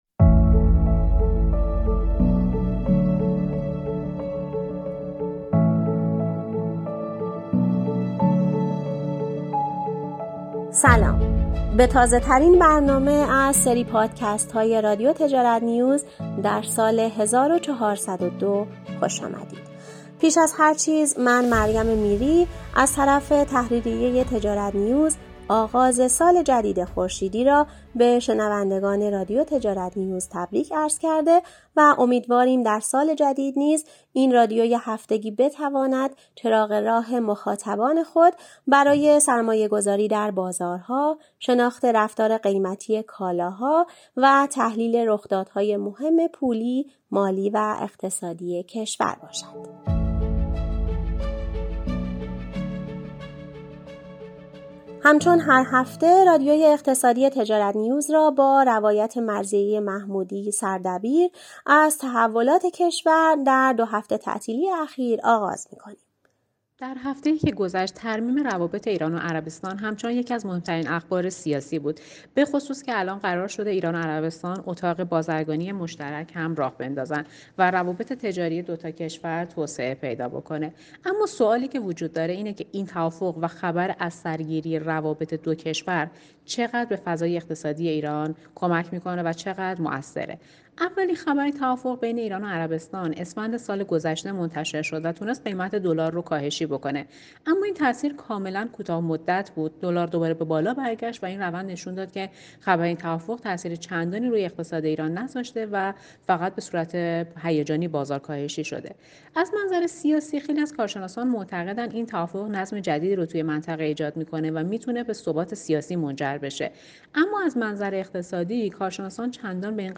در بخش گفتگوی هفته پادکست اقتصادی رادیو تجارت‌نیوز